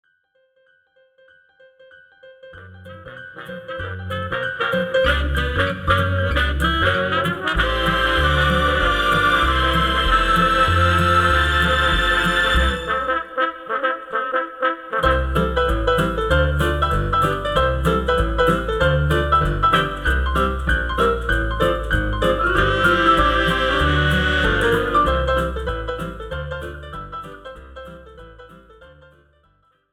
This is an instrumental backing track cover
• Key – G
• Without Backing Vocals
• No Fade